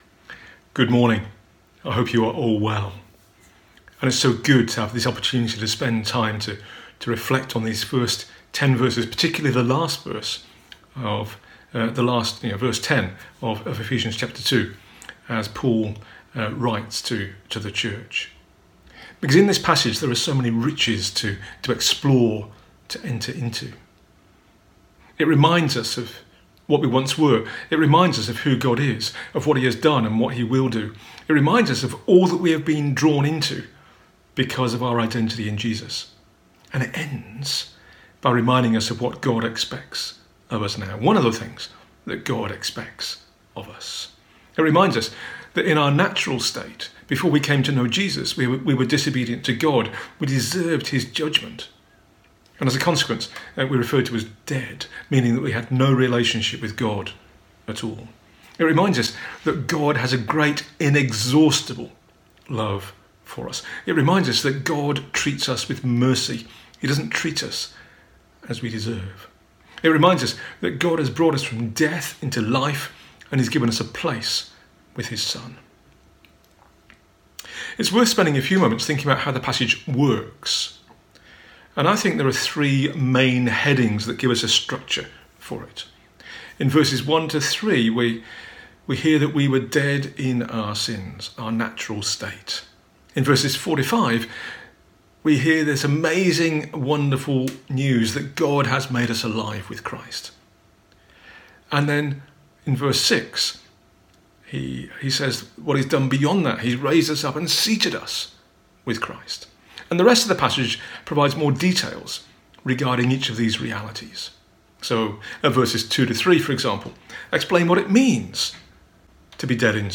A talk from the series "Identity in Christ."